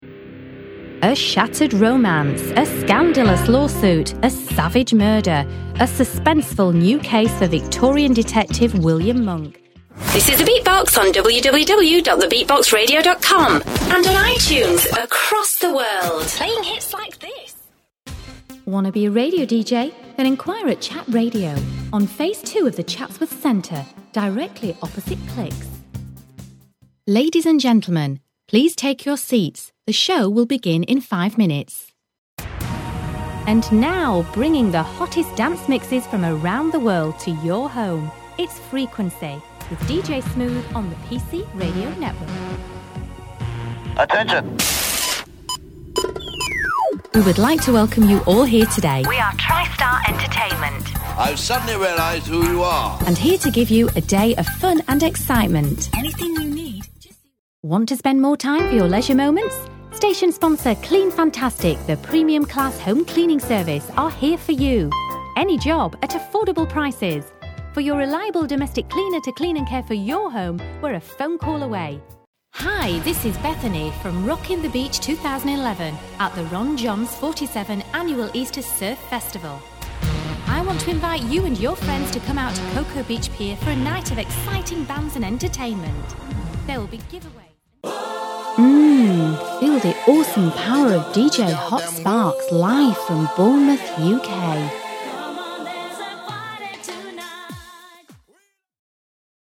britisch
Sprechprobe: Sonstiges (Muttersprache):